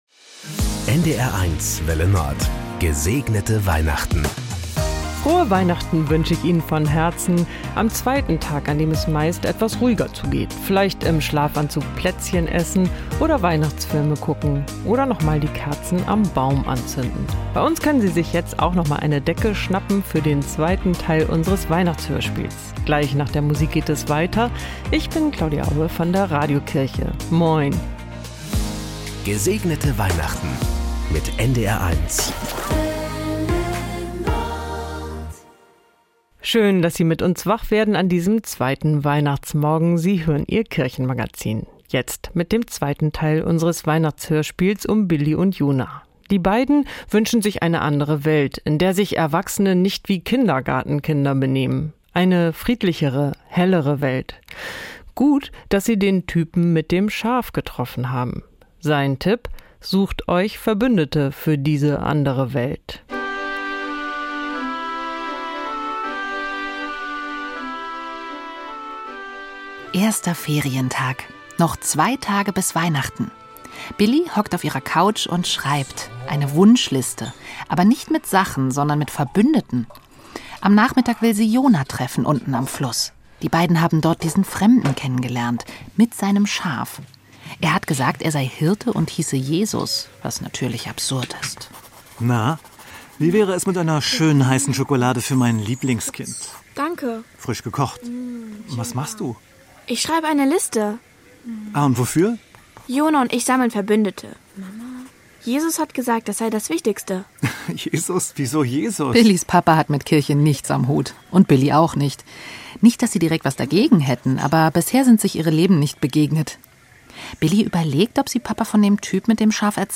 hören Sie den zweiten Teil des Weihnachtshörspiels "Ich bin das Licht!".